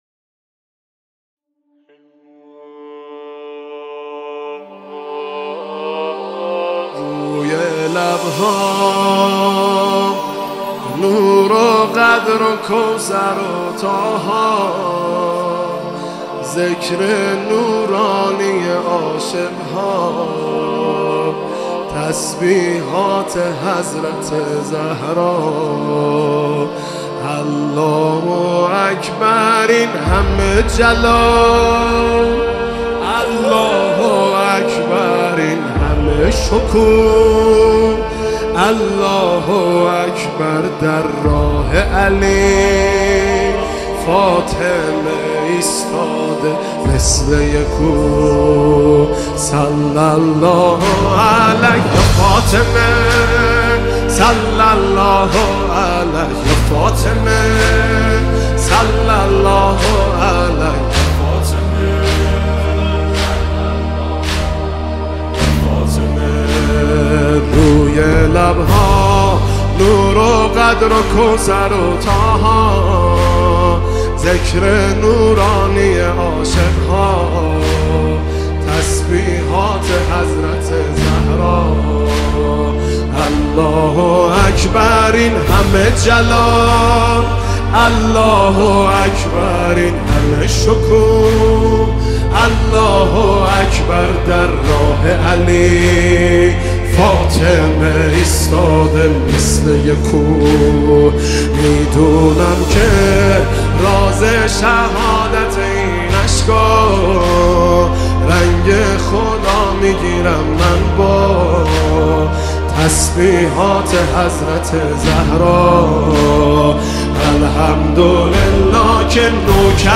اجرا در هيئت ثارالله زنجان